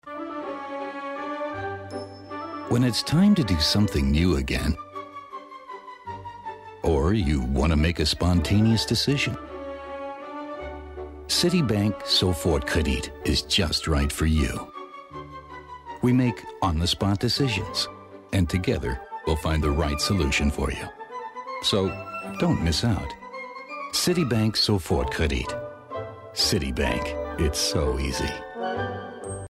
Profi-Sprecher englisch (us).
Kein Dialekt
Sprechprobe: Werbung (Muttersprache):
english voice over artist.